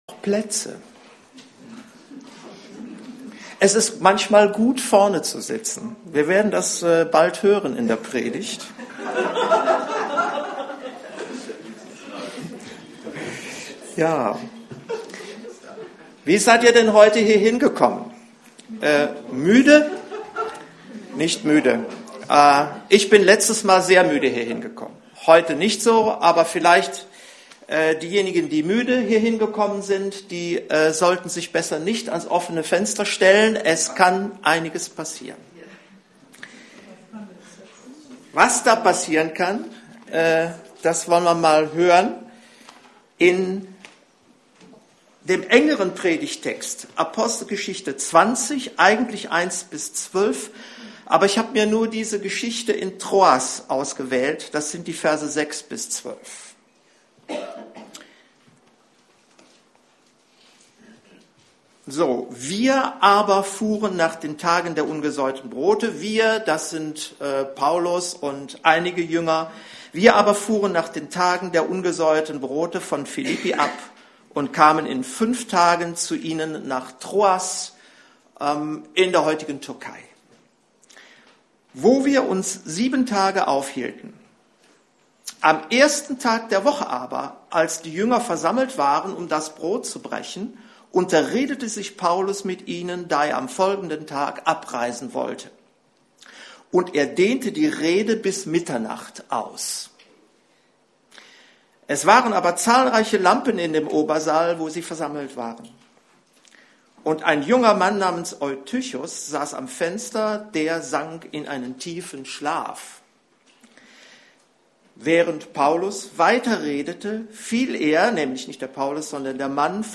Apg 20,17-38 Dienstart: Predigt